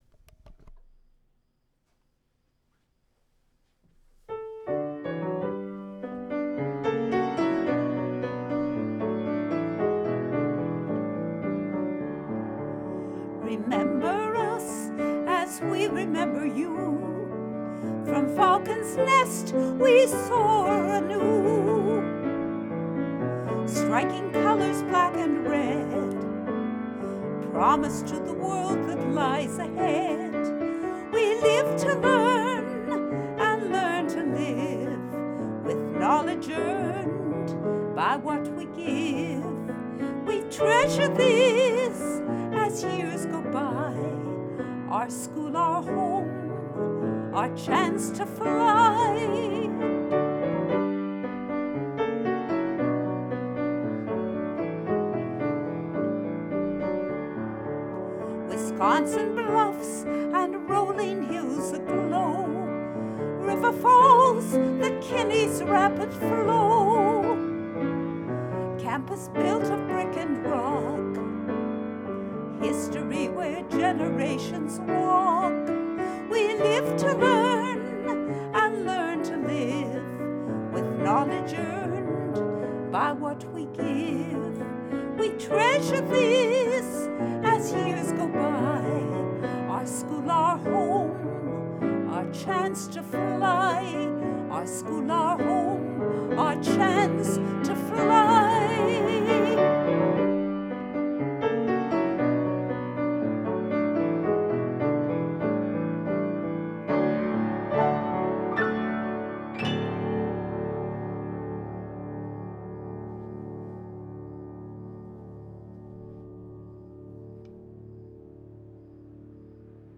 Recording, musical
Piano music, Vocal music, Spirit song, University of Wisconsin-River Falls spirit song, University of Wisconsin-River Falls